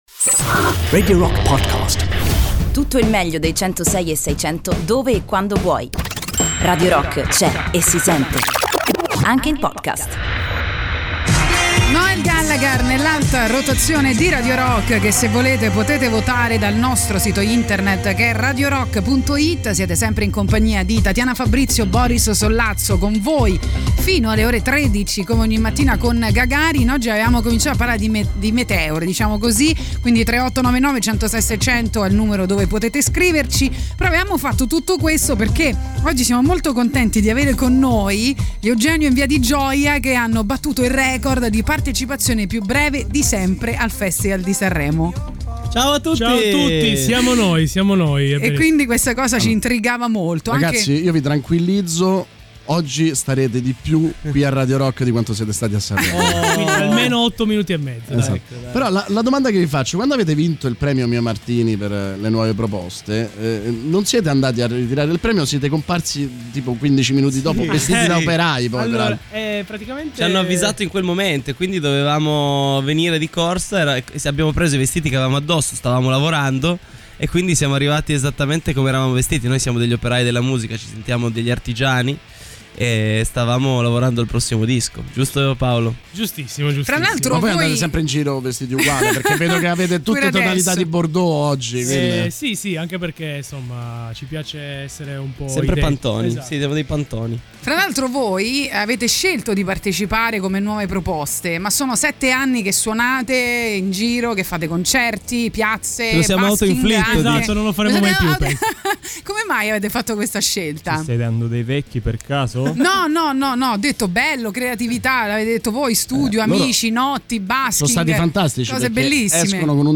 Intervista: Eugenio in Via Di Gioia (12-02-20)
negli studi di Radio Rock